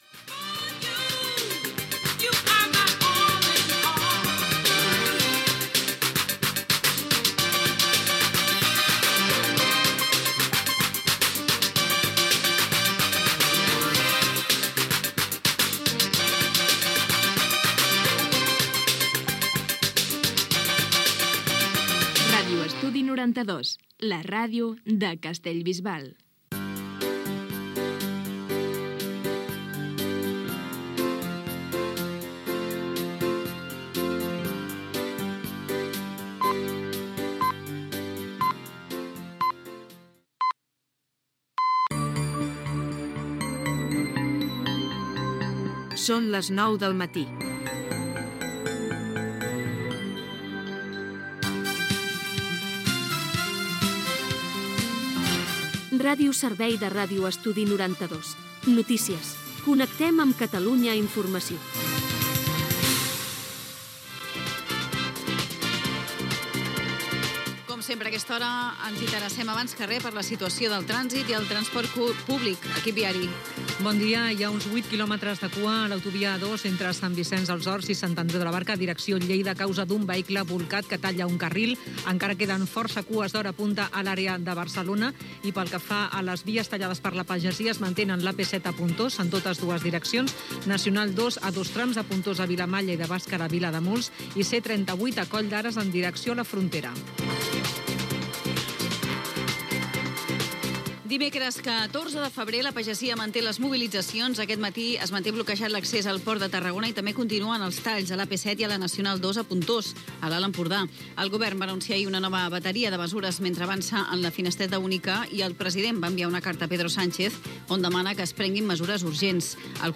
Tema musical, indicatiu de l'emissora, hora, connexió amb Catalunya Informació (mobilitzacions de la pagesia catalana, guerra d'Ucraïna, comissió del Parlament que investiga les conseqüències de la COVID 19 a les residències de gent gran , etc.).
Informatiu